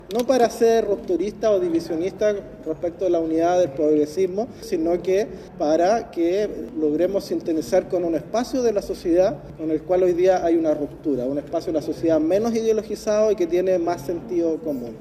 En ese sentido, Raúl Soto, jefe de bancada de los diputados PPD e independientes, sostuvo que el Socialismo Democrático no solo buscará ser respetado, sino que también trabajará en su reorganización y fortalecimiento.